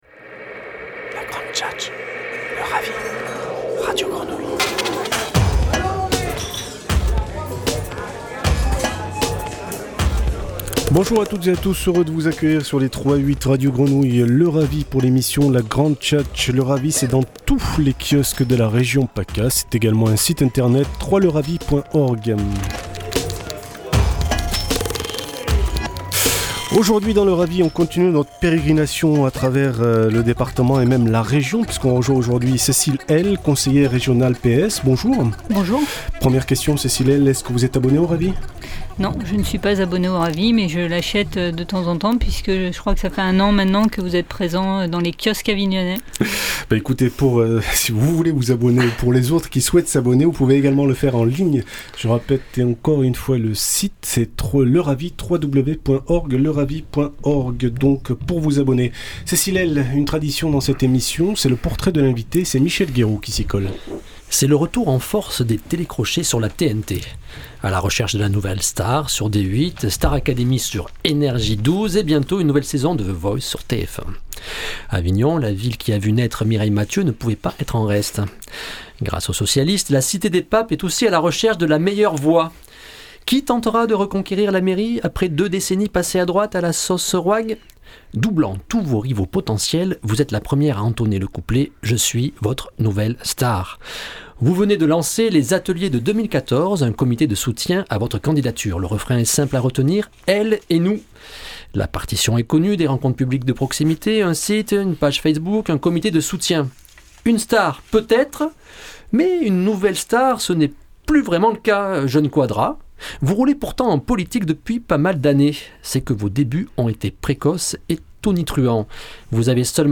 Entretien radio en partenariat avec Radio Grenouille